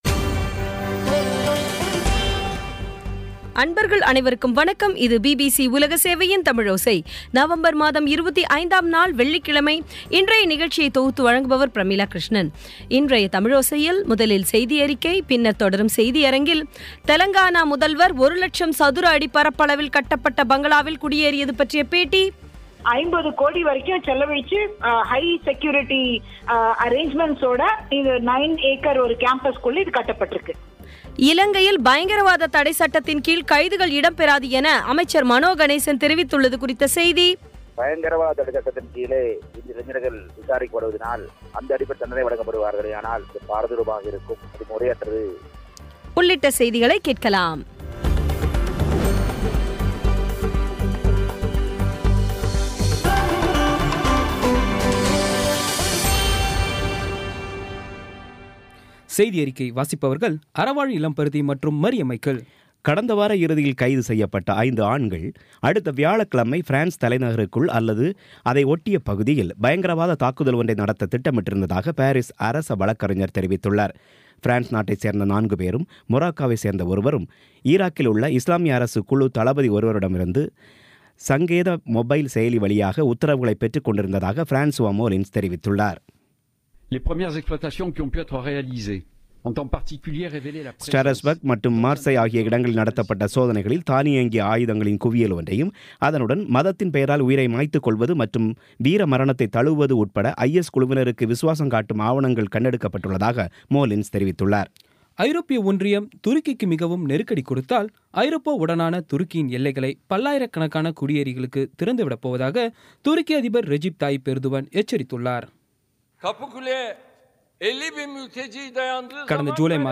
இன்றைய தமிழோசையில், முதலில் செய்தியறிக்கை, பின்னர் தொடரும் செய்தியரங்கத்தில்